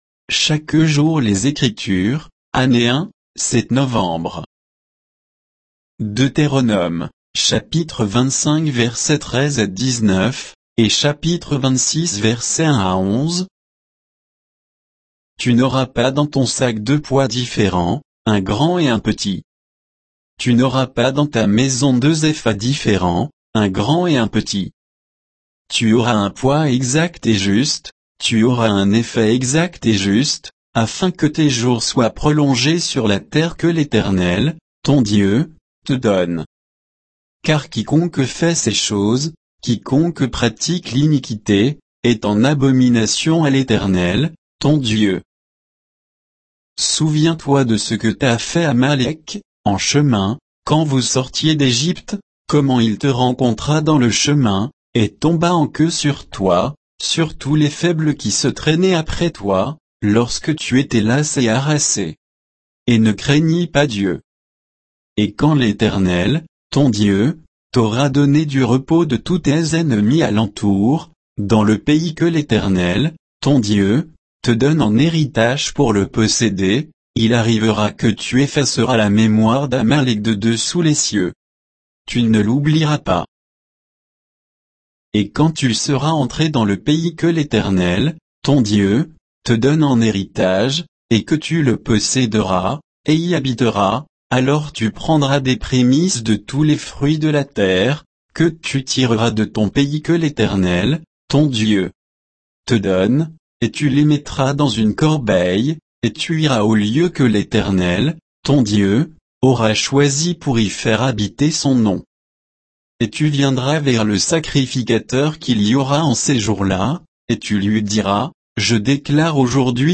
Méditation quoditienne de Chaque jour les Écritures sur Deutéronome 25